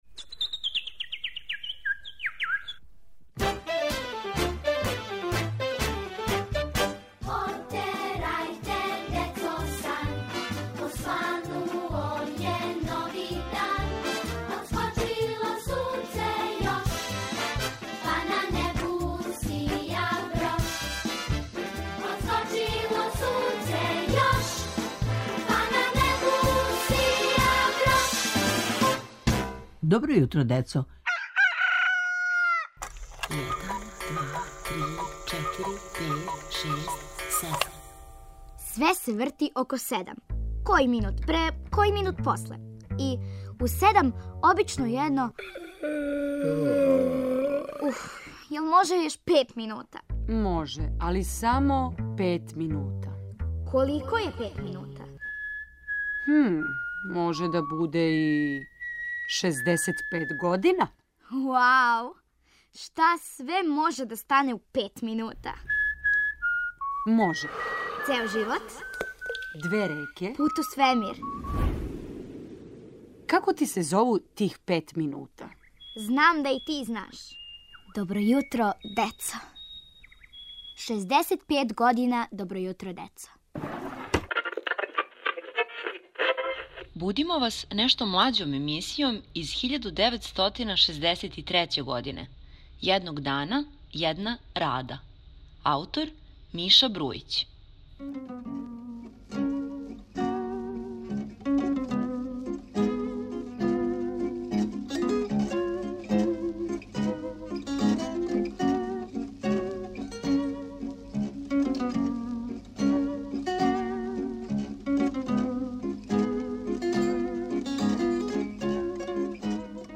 Поводом 65. рођендана Добро јутро децо, слушате нешто млађу емисију из 1963. године аутора Мише Брујића. Зове се: "Једног дана једна Рада" и ово је први део приче.